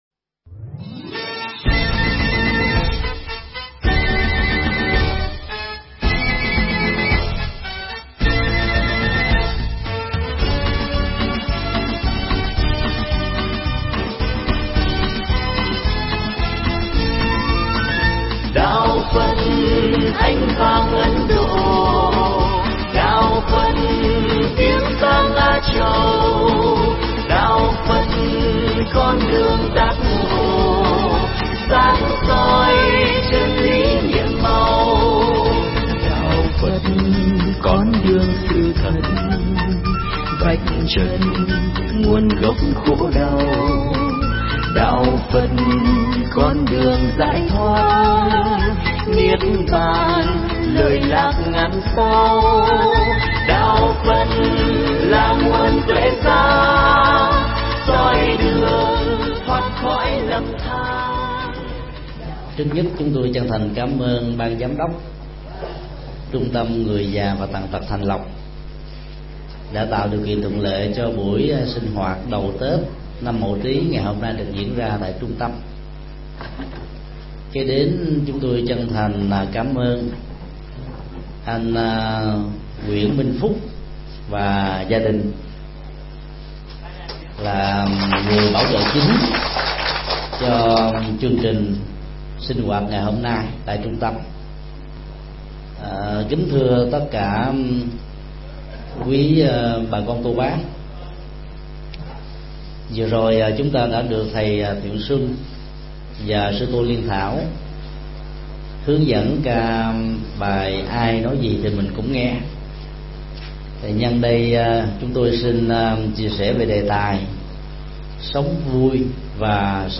Mp3 Pháp âm Sống vui sống khỏe – thầy Thích Nhật Từ giảng tại Trung tâm người già và tàn tật Thạnh Lộc, Q.12, ngày 13 tháng 02 năm 2008